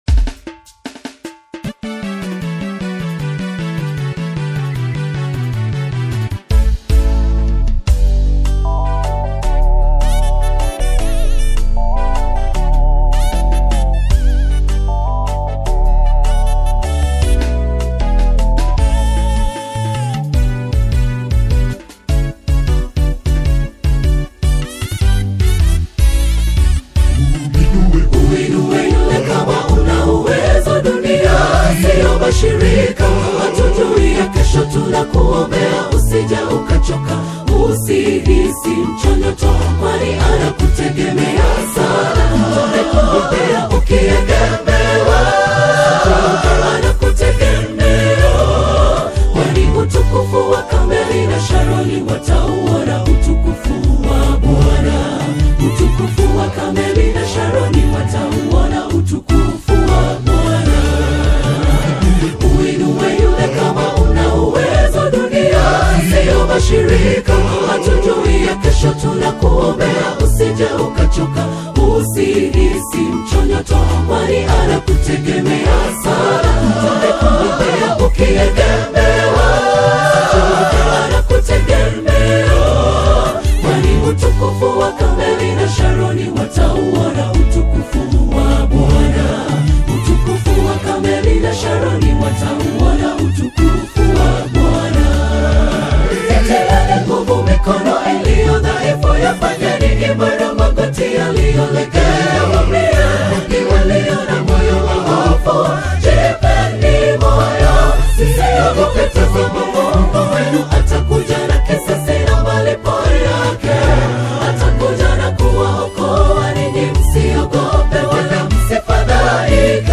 The East African gospel scene